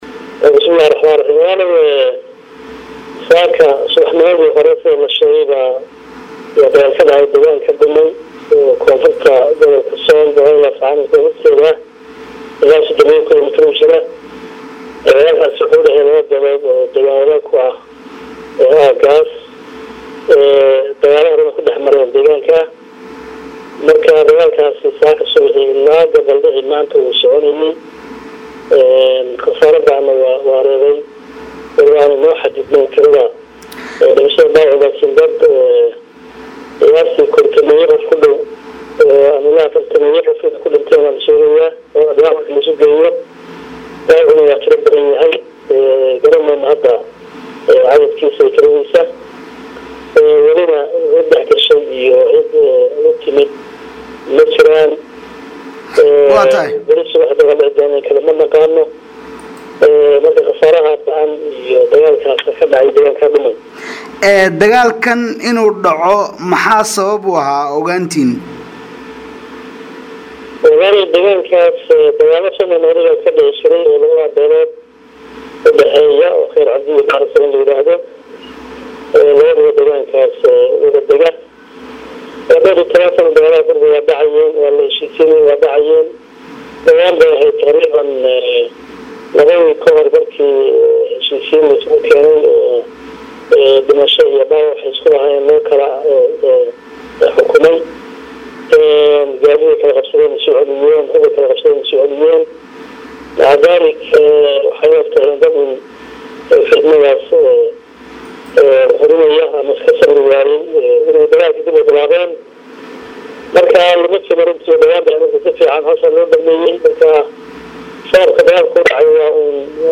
Wareeysi: Dagaalkii Gobolka Sool oo la isku gabaldhacsaday iyo qasaaraha oo sii kordhay